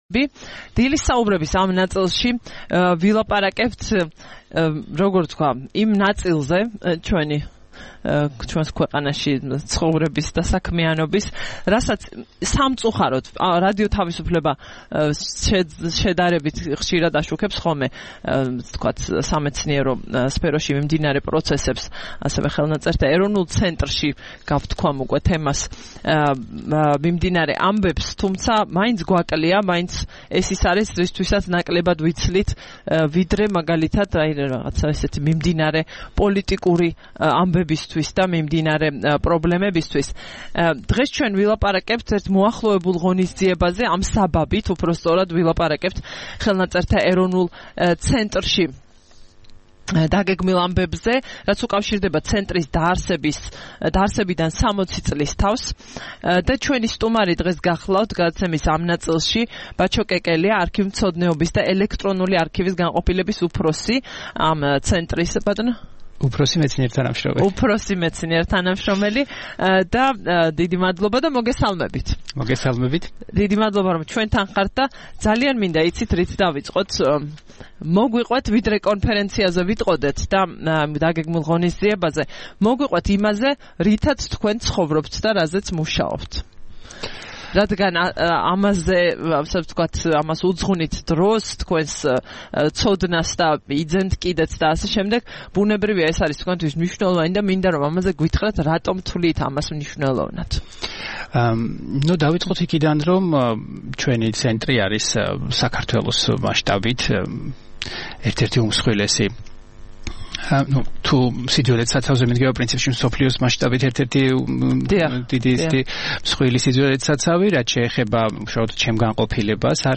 26 ივნისს რადიო თავისუფლების "დილის საუბრების" სტუმრები იყვნენ: